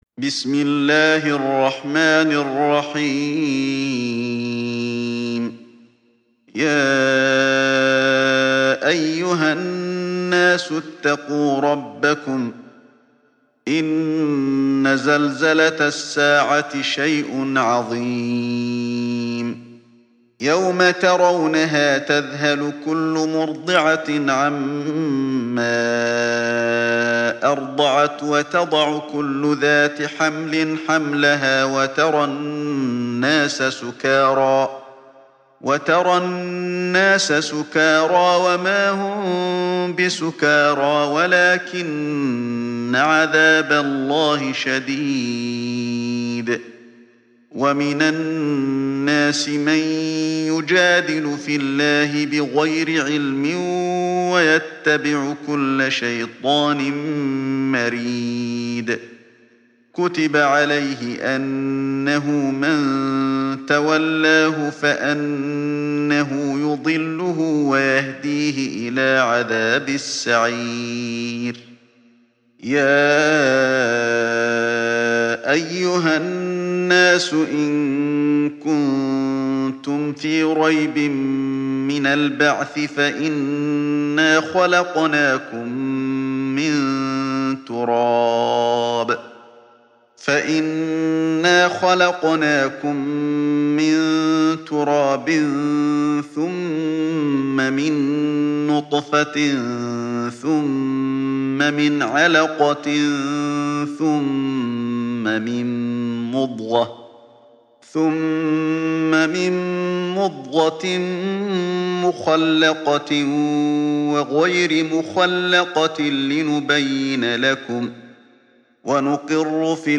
Sourate Al Haj Télécharger mp3 Ali Alhodaifi Riwayat Hafs an Assim, Téléchargez le Coran et écoutez les liens directs complets mp3